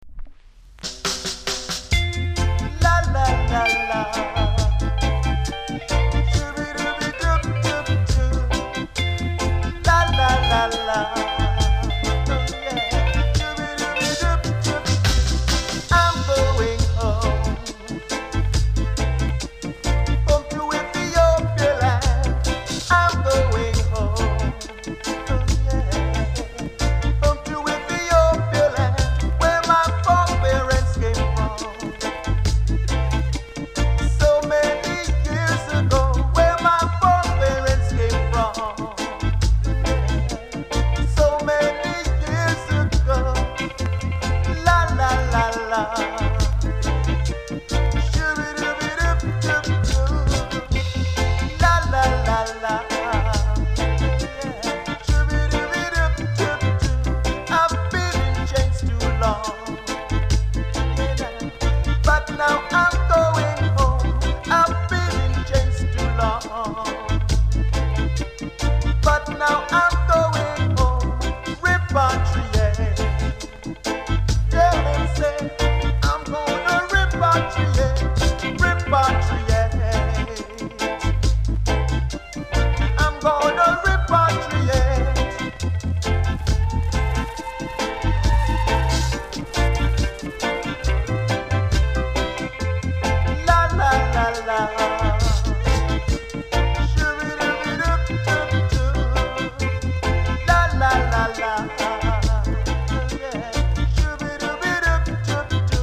※盤は概ねキレイですが、最後の方で少しジリジリする箇所(試聴の最後の方で確認出来ます)があります。